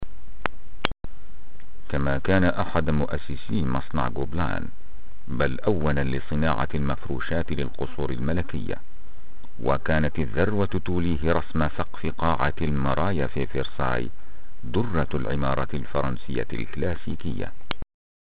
voix grave ,lecture documentaire doublage series ,spot pub.arabe letteraire sans accent.
Sprechprobe: eLearning (Muttersprache):
arabian male voice artist littere